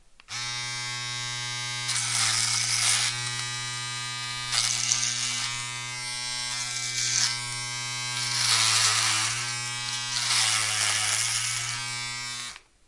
电子产品 " 剃须刀 1
描述：用电动剃须刀剃须。
Tag: 剃须 电子 剃须刀